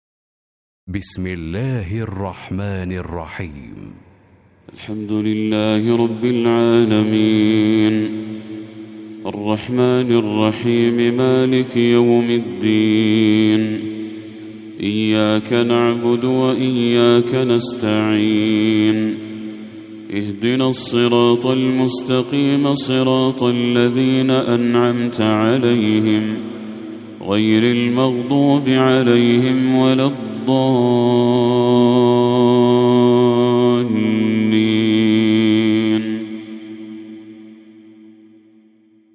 سورة الفاتحة Surat-Al-Fatiha > المصحف المرتل للشيخ بدر التركي > المصحف - تلاوات الحرمين